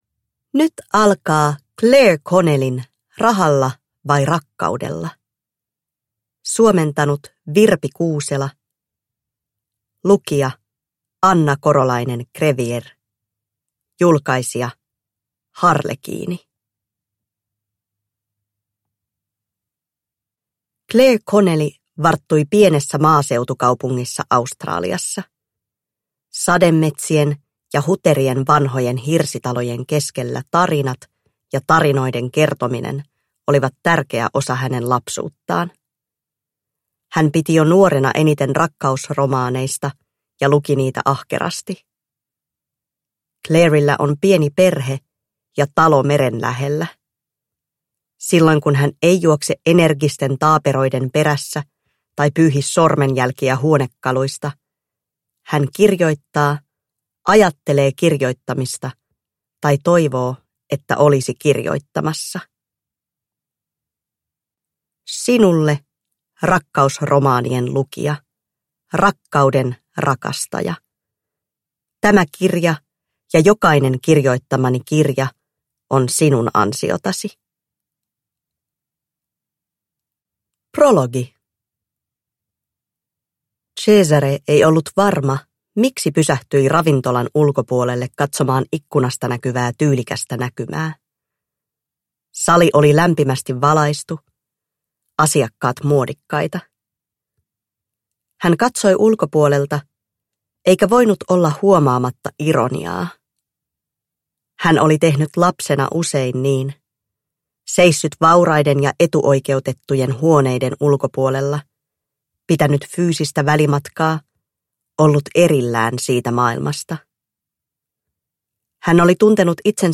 Rahalla vai rakkaudella? (ljudbok) av Clare Connelly